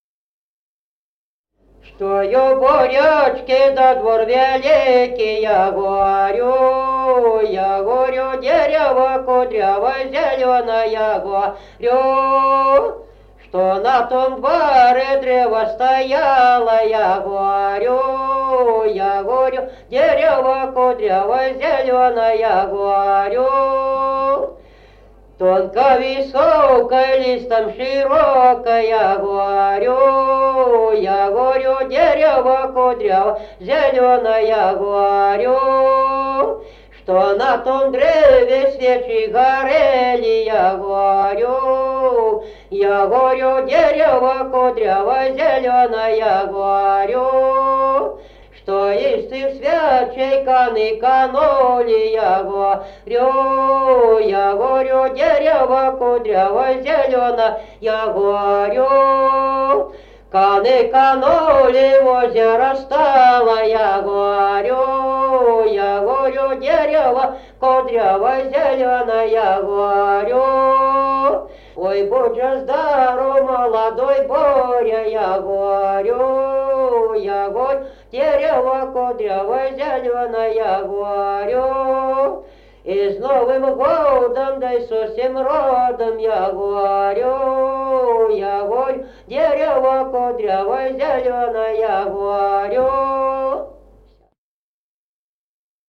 Народные песни Стародубского района «Чтой у Боречки», новогодняя щедровная.
1953 г., д. Камень.